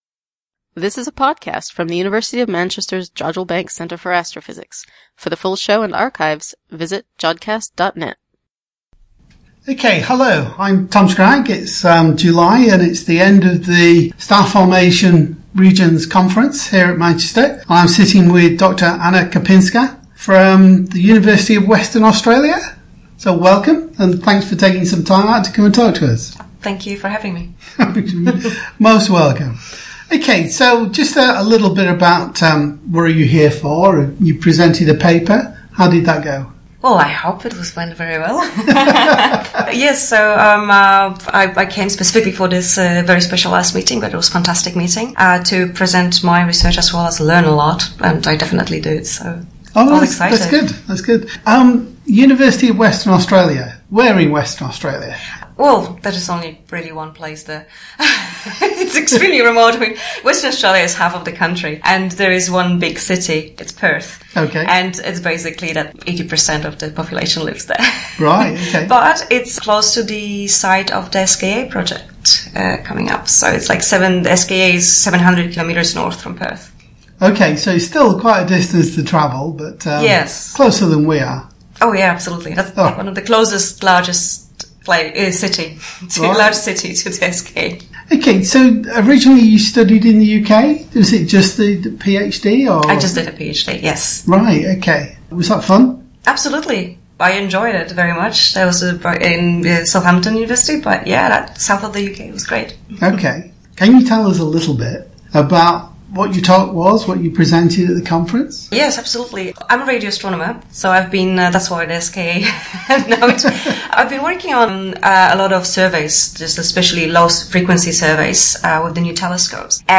In the show this time, we present a series of interviews from the Measuring Star Formation in the Radio, Millimetre, and Submillimetre meeting help at the Jodrell Bank Centre for Astrophysics on 24-26 July. This meeting was an opportunity for people from around the world to come together to discuss their work in studying star formation, mainly extragalcatic star formation, in this part of the electromagnetic spectrum.
Interview